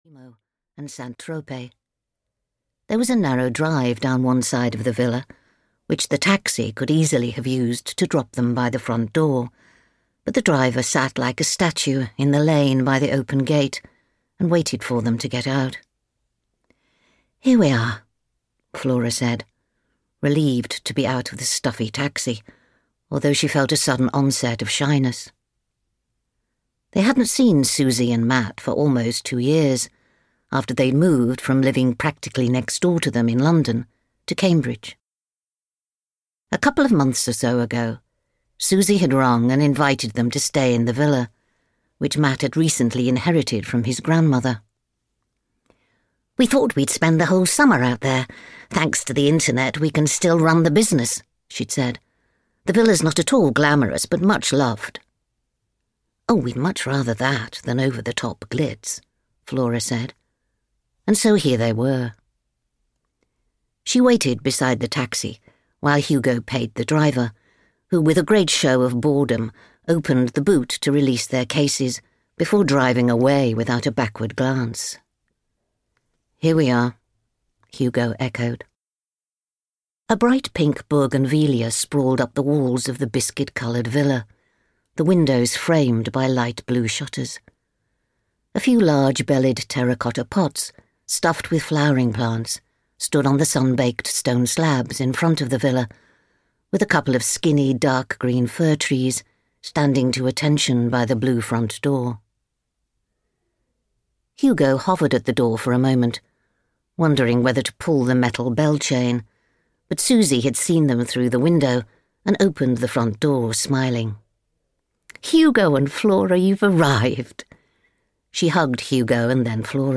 Ukázka z knihy
that-long-lost-summer-en-audiokniha